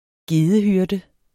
Udtale [ ˈgeːðə- ]